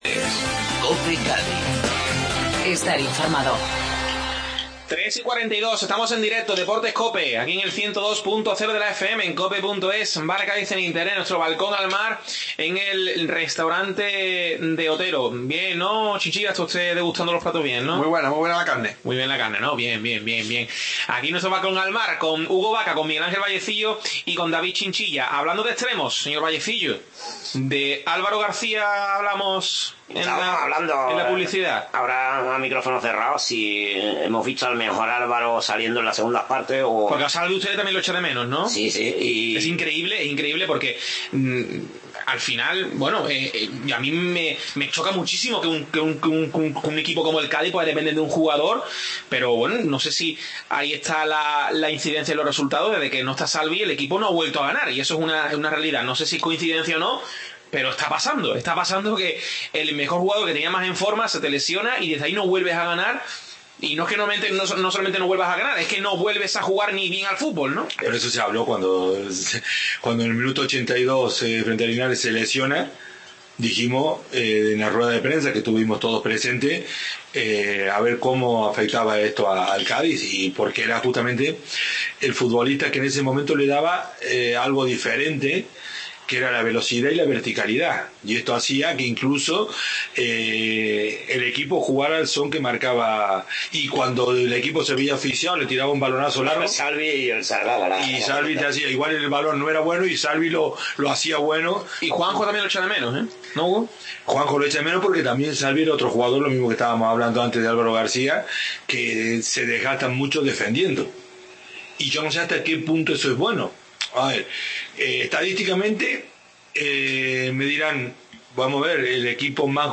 AUDIO: Segunda parte de la tertulia analizando la actualidad del Cádiz